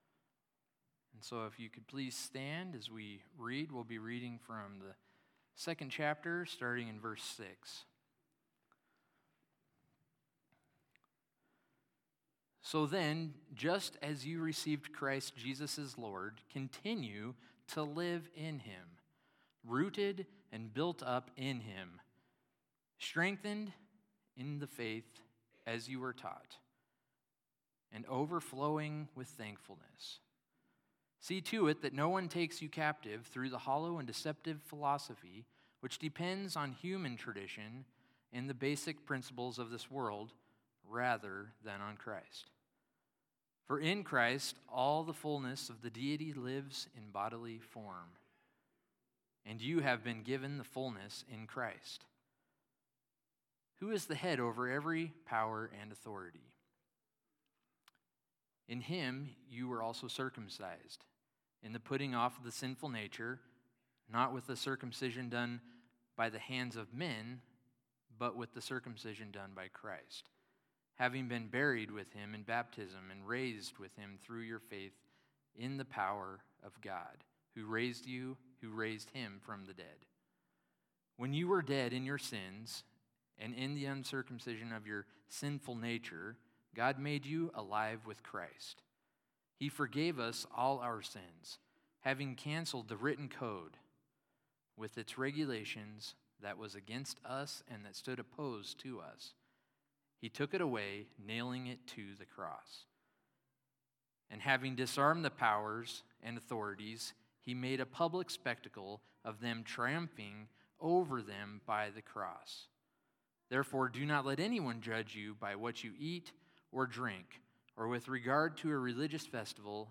Sermons Archive - Page 5 of 21 - Bethel Nazarene